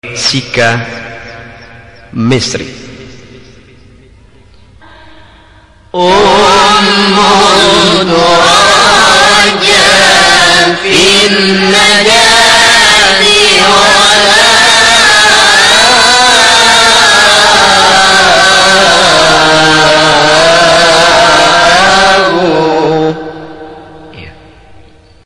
سگاه اصلی جواب جواب.mp3